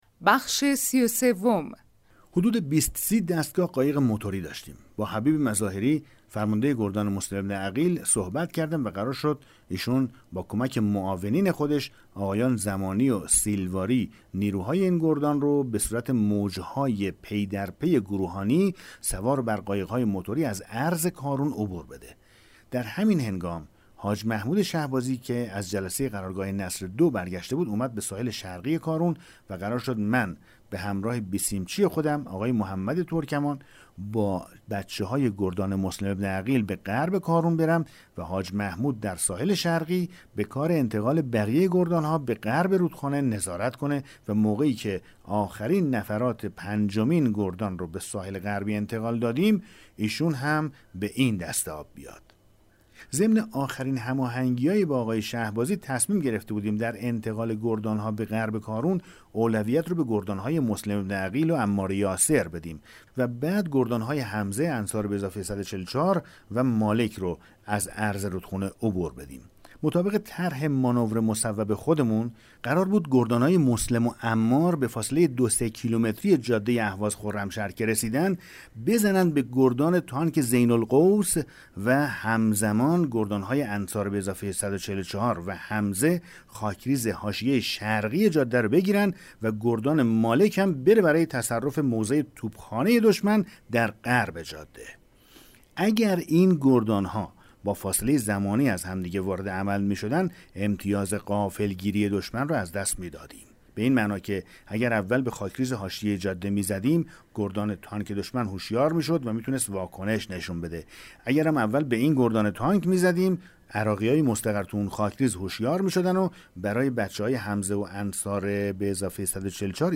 کتاب گویا